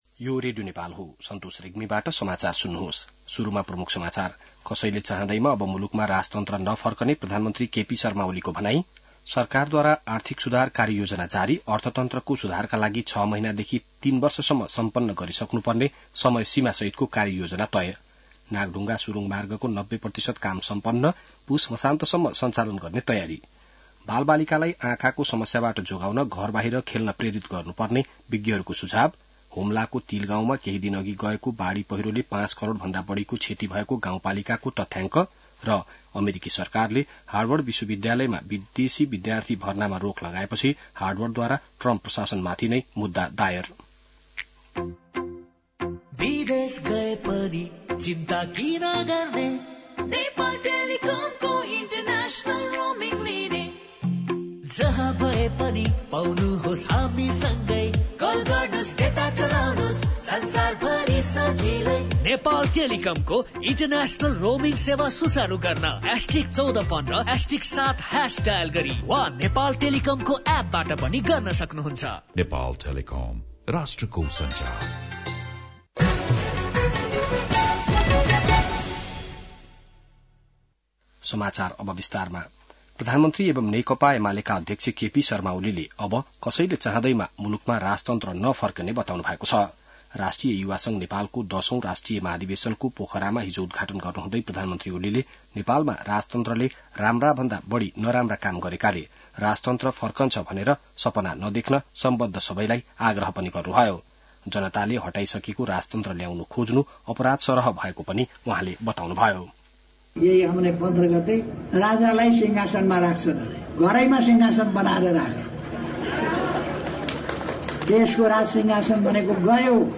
बिहान ७ बजेको नेपाली समाचार : १० जेठ , २०८२